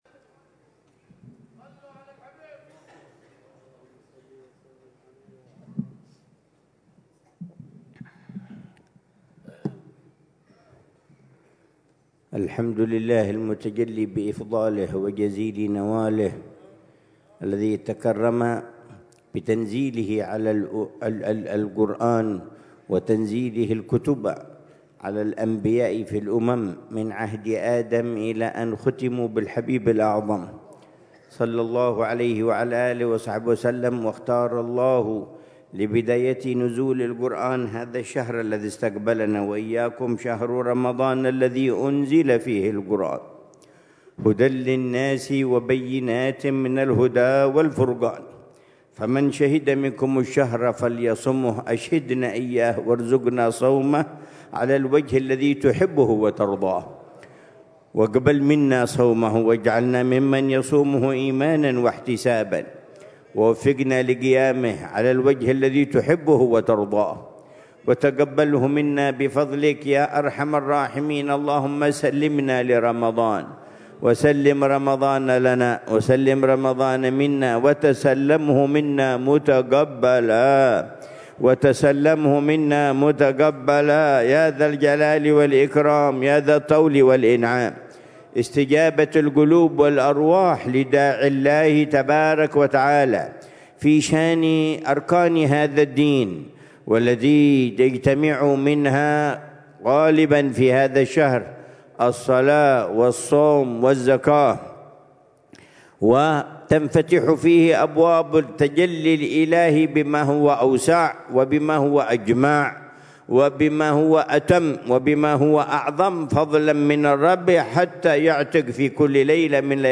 محاضرة العلامة الحبيب عمر بن محمد بن حفيظ ضمن سلسلة إرشادات السلوك في دار المصطفى، ليلة الجمعة 29 شعبان 1446هـ، بعنوان: